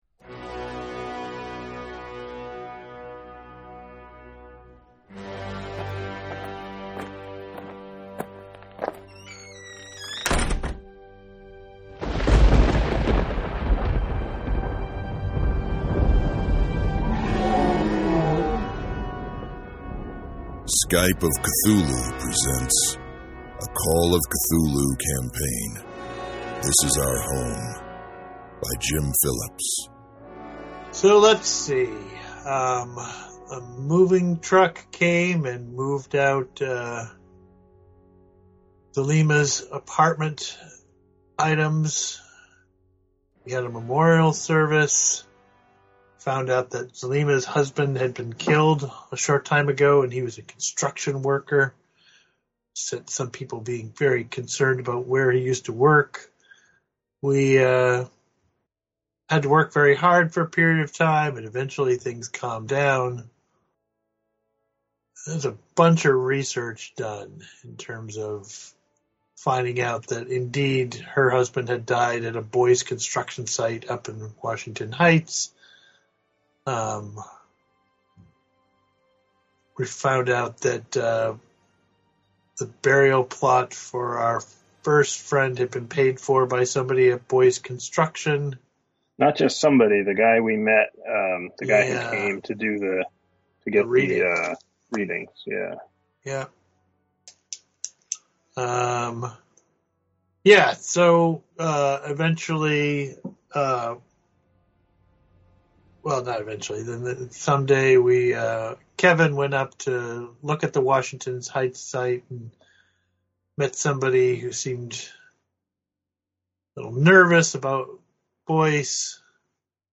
Skype of Cthulhu presents a Call of Cthulhu scenario.